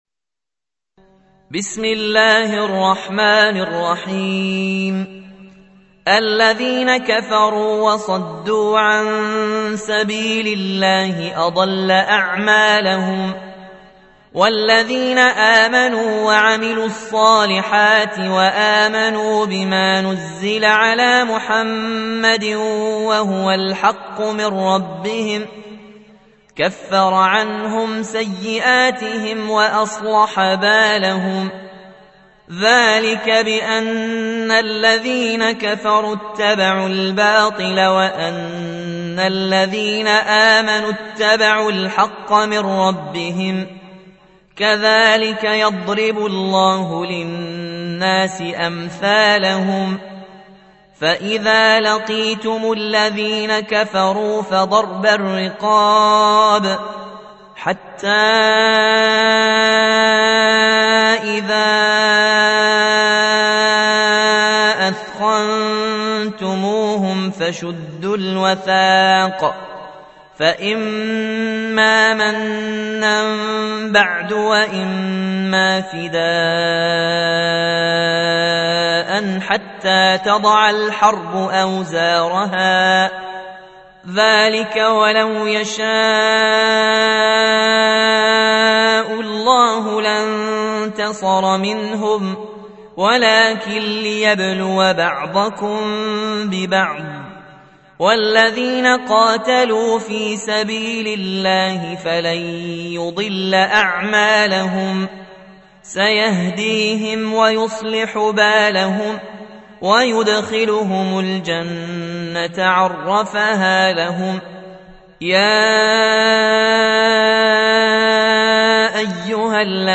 47. سورة محمد / القارئ